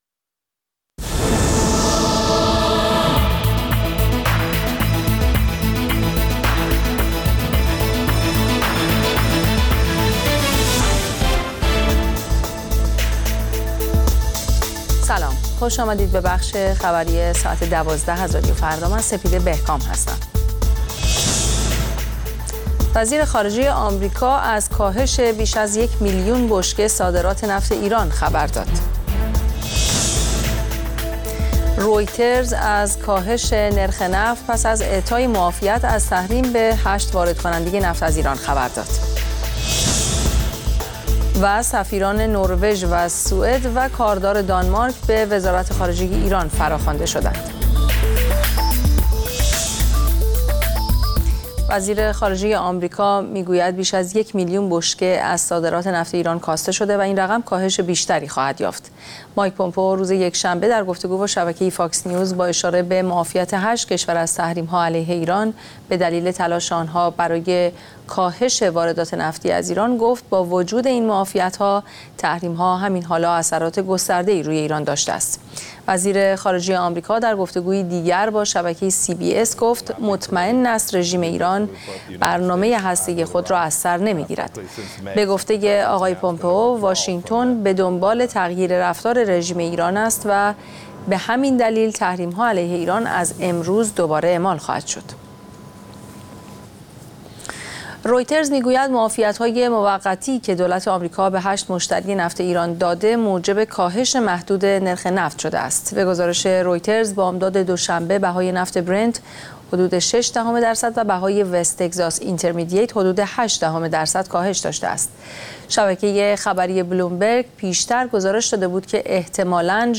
اخبار رادیو فردا، ساعت ۱۲:۰۰